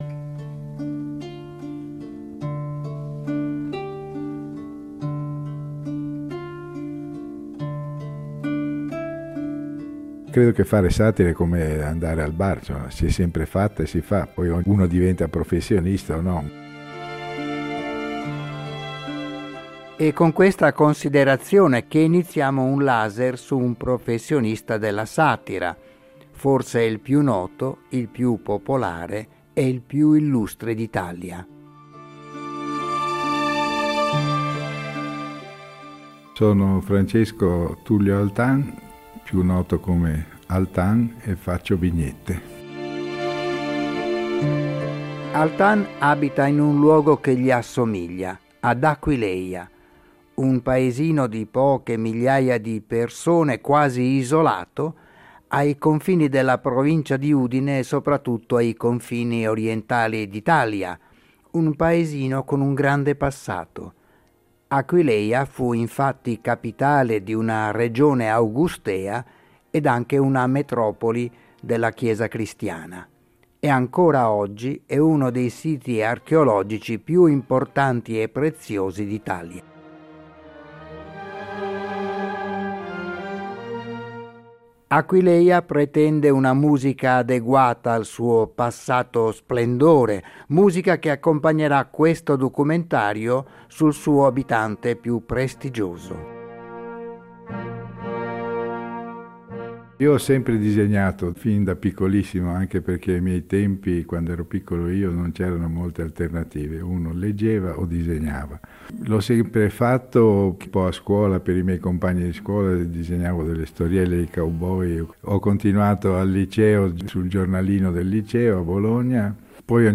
Intervistato nella sua casa di Aquileia, Altan racconta il suo percorso artistico-professionale, iniziato più di mezzo secolo fa in Brasile.